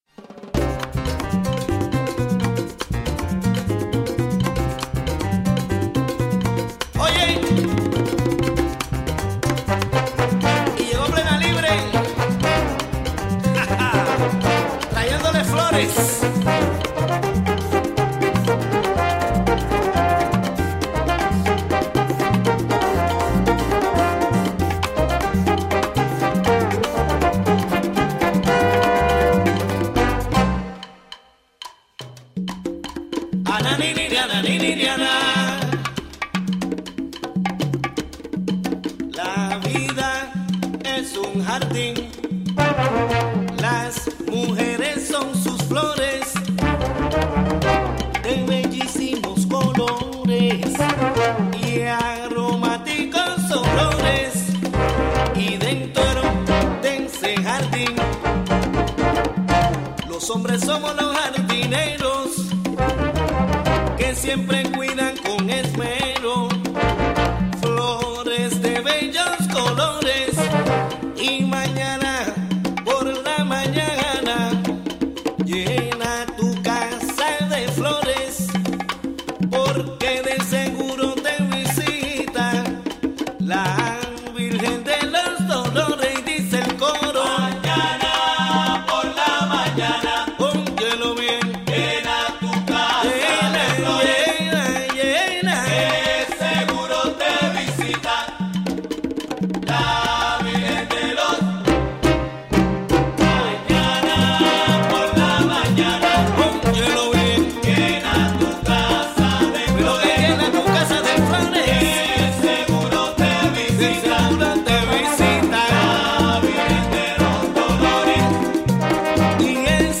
A discussion about the different ways of considering the terms Latino or Hispanic. Conversation